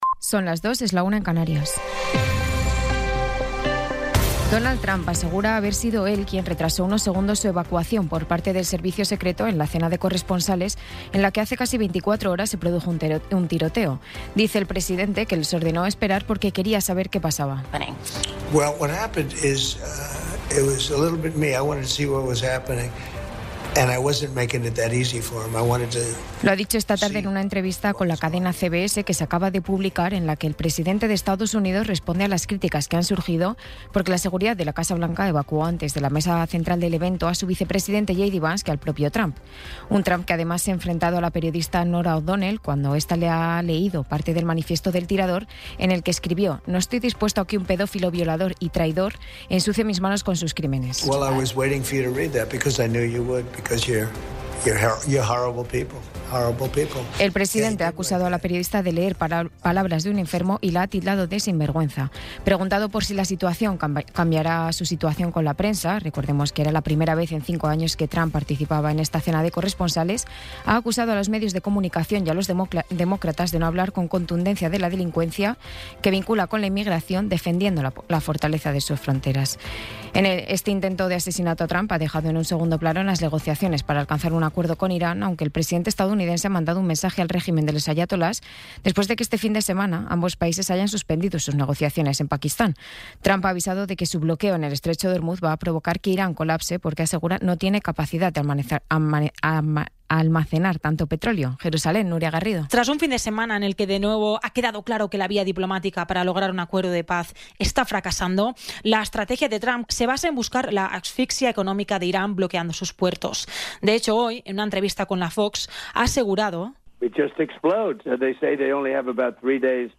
Resumen informativo con las noticias más destacadas del 27 de abril de 2026 a las dos de la mañana.